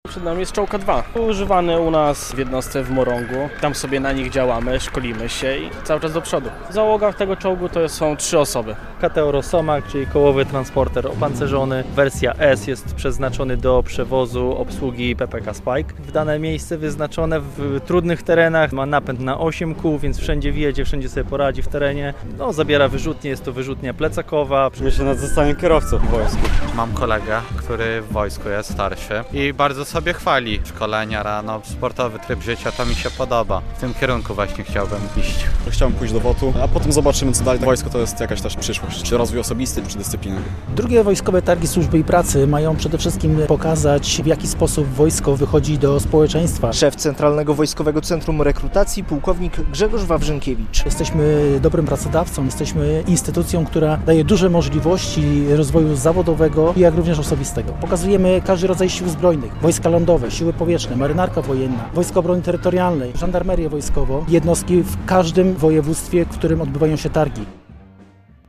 II Wojskowe Targi Służby i Pracy w Białymstoku - relacja